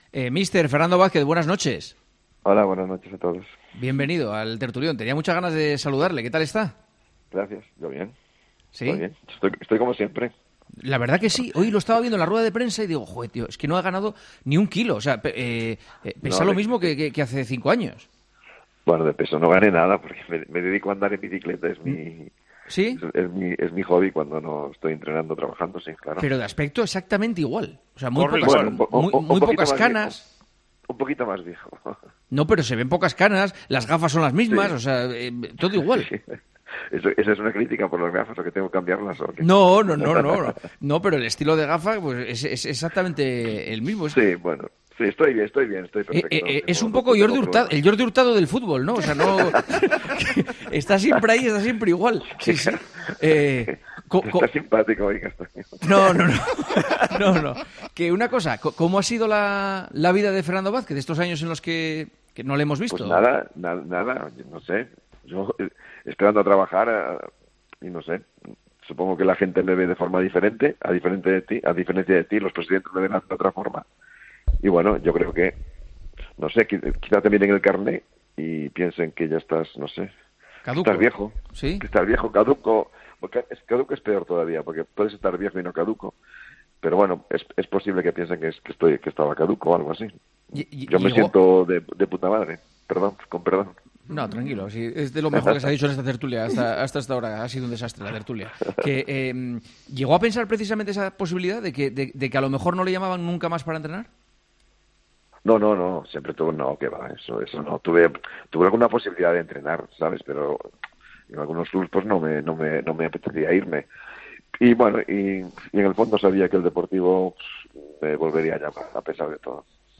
AUDIO: Entrevistamos al entrenador del Deportivo, que nos habla de la ilusión de la afición por lograr la salvación.